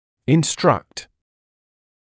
[ɪn’strʌkt][ин’стракт]инструктировать; давать указания